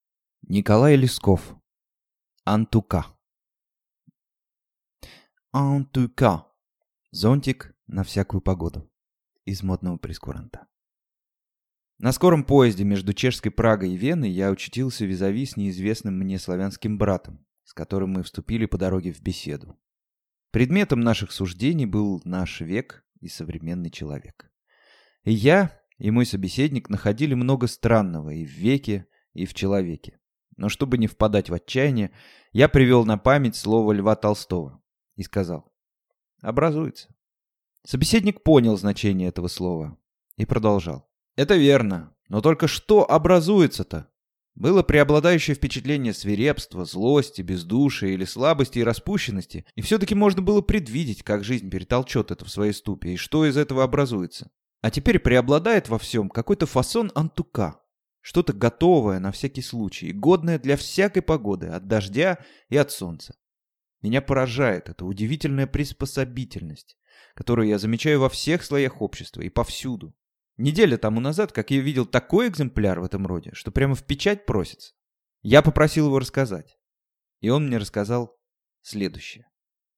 Аудиокнига Антука | Библиотека аудиокниг